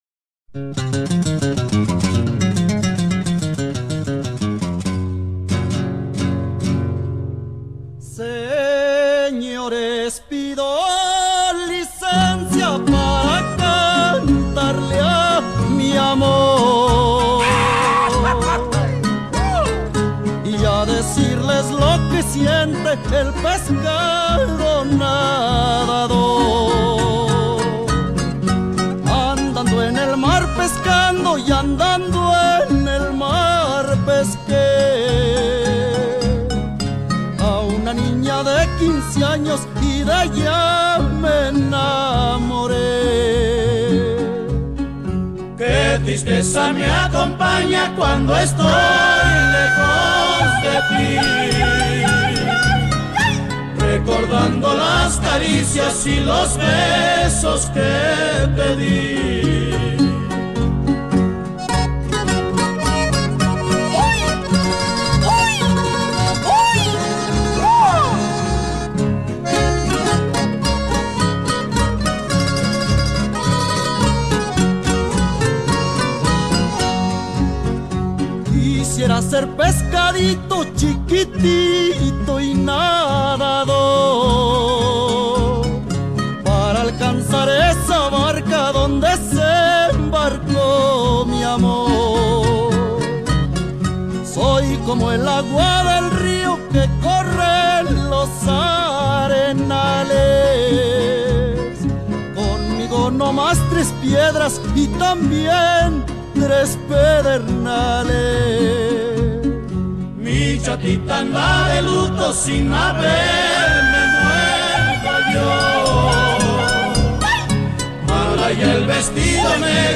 Entre bachata y canción ranchera